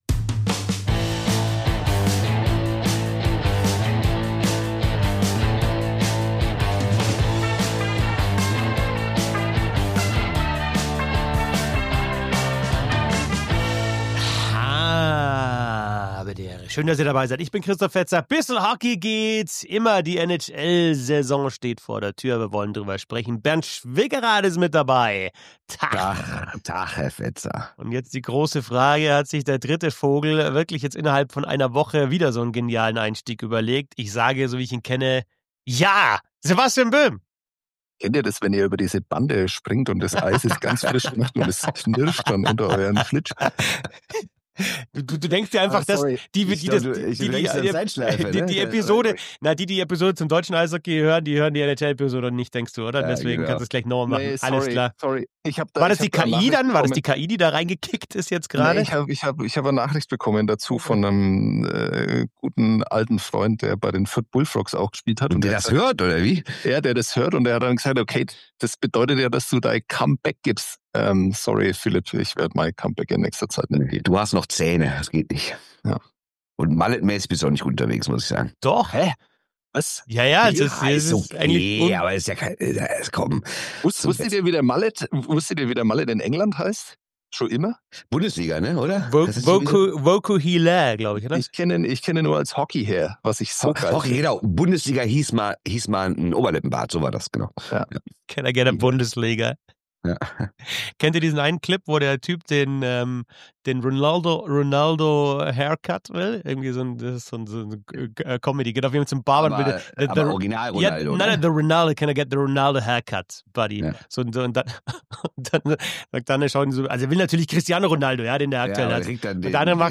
Dazu gibt es das Quiz "Eiiinen hab ich noch".